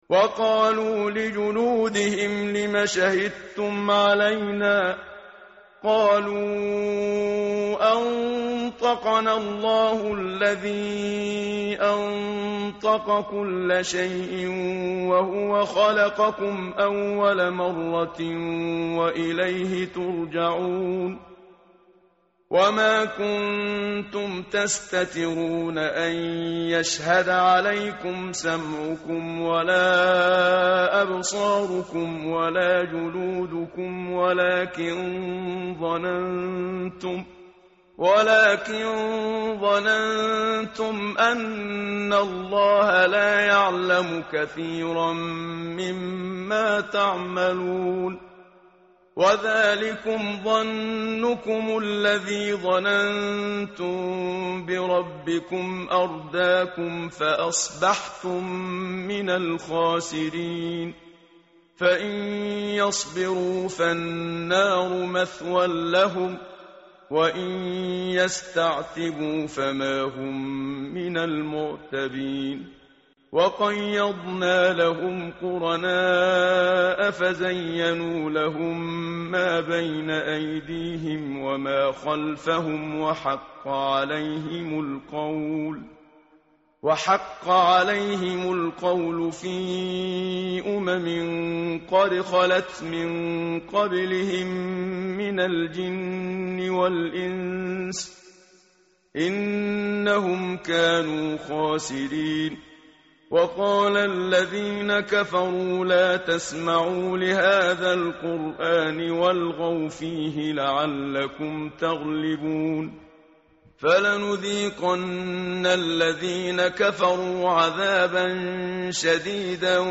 متن قرآن همراه باتلاوت قرآن و ترجمه
tartil_menshavi_page_479.mp3